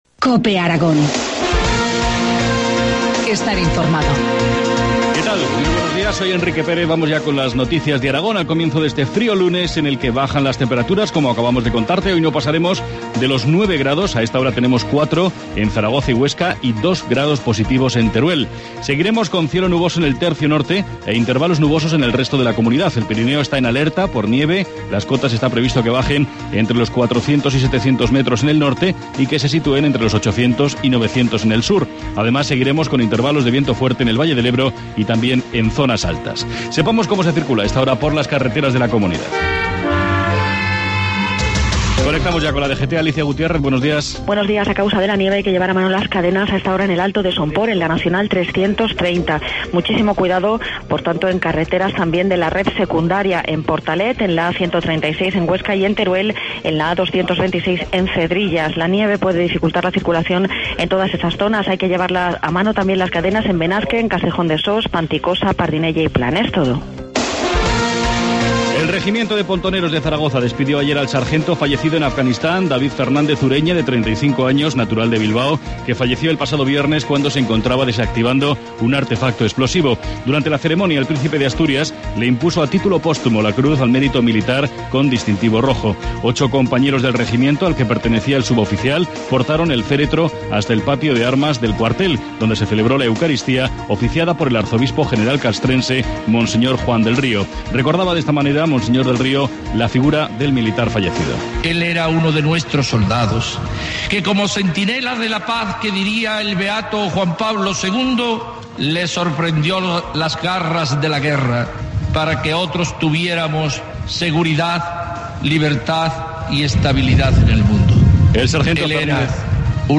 Informativo matinal, lunes 14 de enero, 7.25 horas